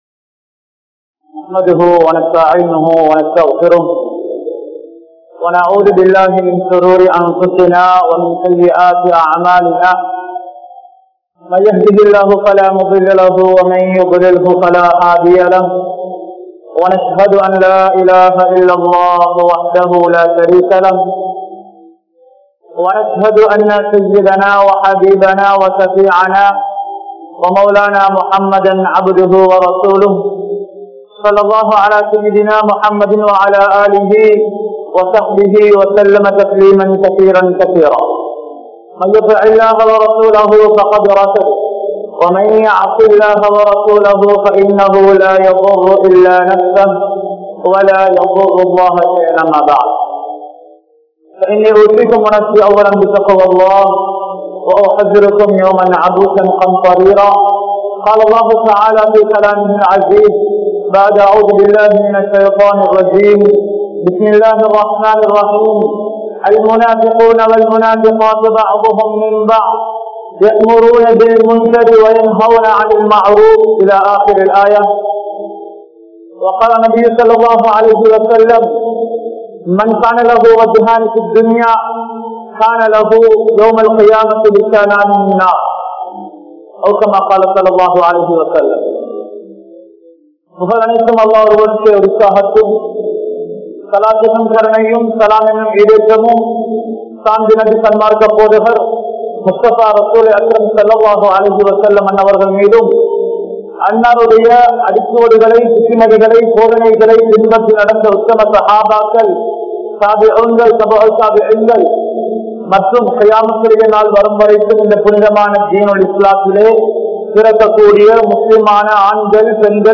Munafiq Enpavan Yaar? (முனாபிக் என்பவன் யார்?) | Audio Bayans | All Ceylon Muslim Youth Community | Addalaichenai
Matala, Hanafi Jumua Masjidh